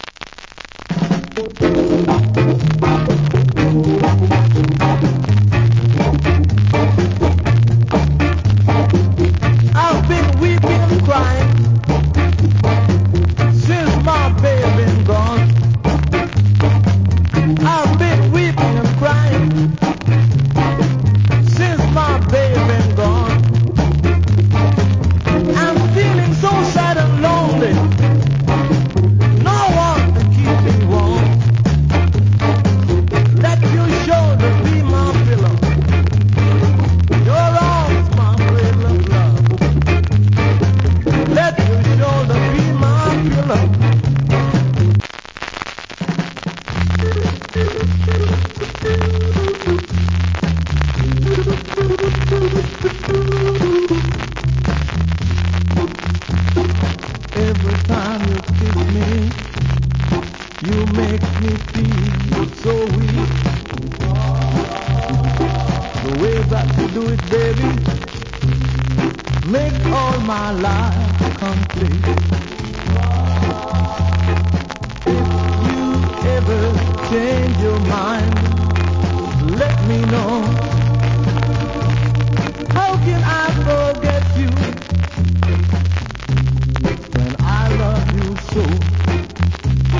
60's Wicked Vocal. UK Production.